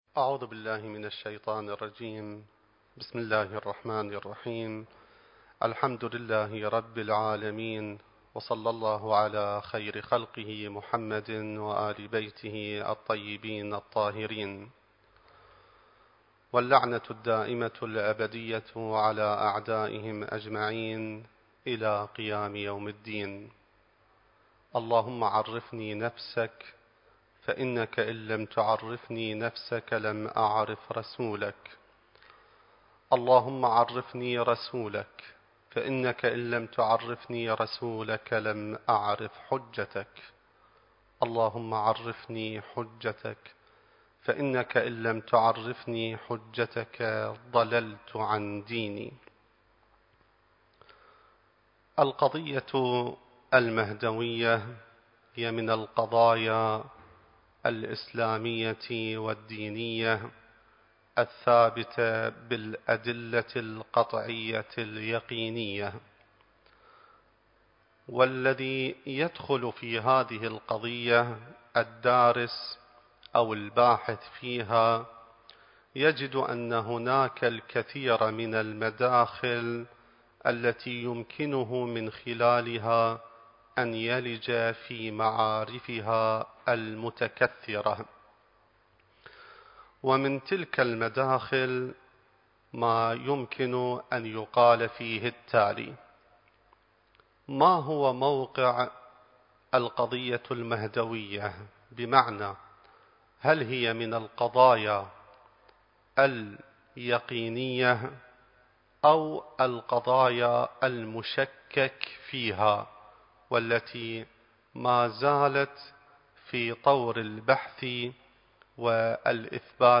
المكان: العتبة العلوية المقدسة الزمان: ذكرى ولادة الإمام المهدي (عجّل الله فرجه) التاريخ: 2021